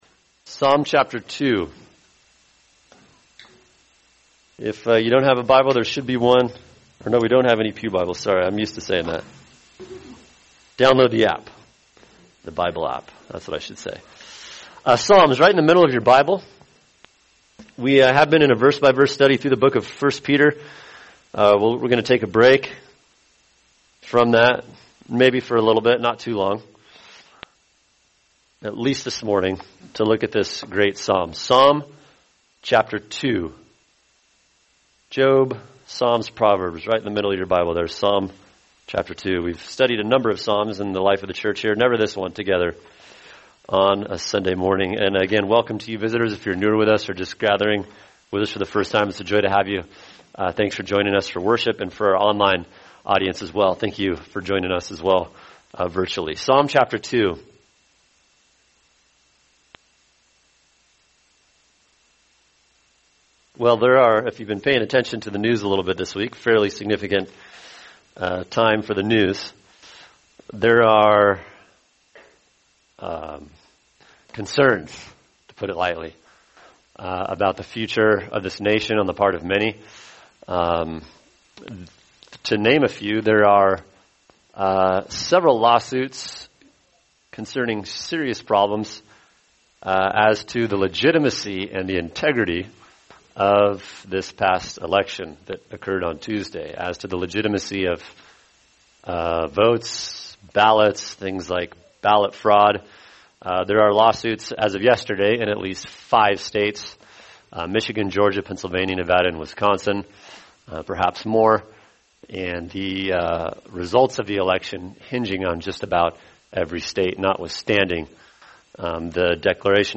[sermon] Psalm 2 | Cornerstone Church - Jackson Hole